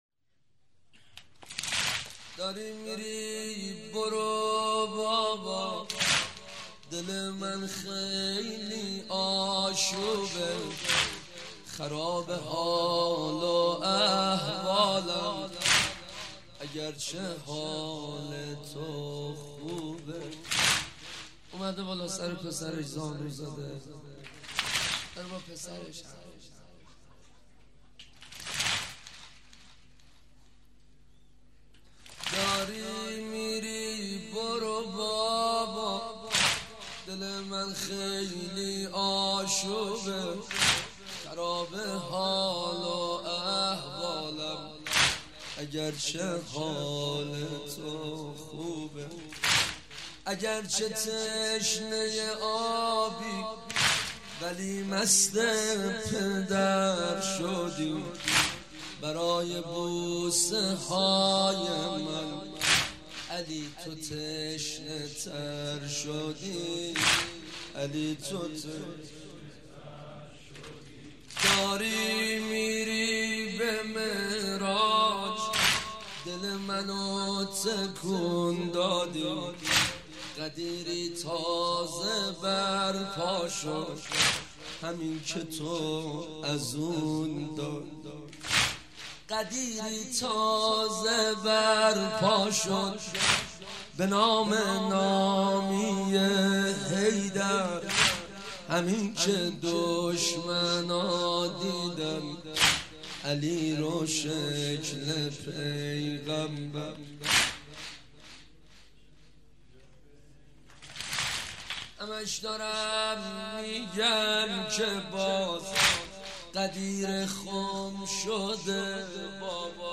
مناسبت : شب هشتم محرم